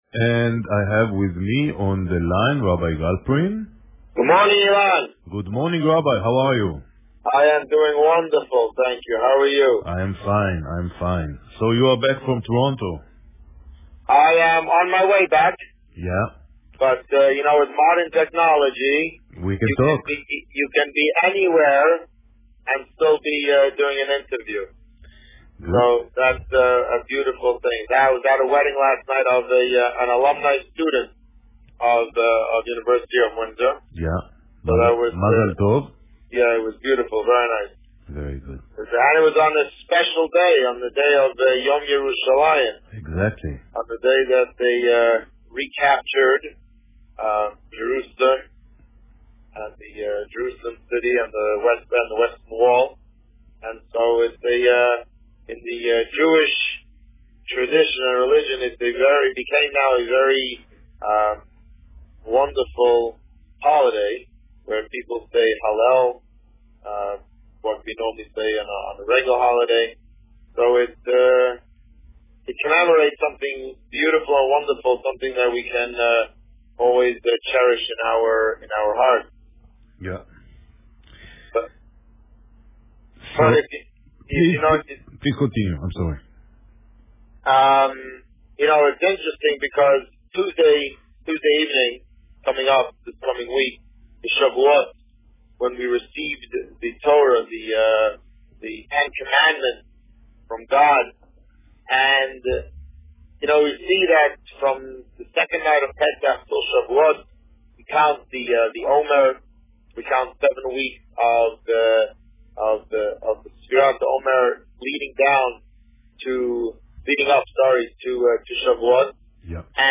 This week, the Rabbi is on his way back from Toronto, and spoke about the upcoming holiday of Shavuot.  Listen to the interview